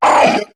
Cri de Gloupti dans Pokémon HOME.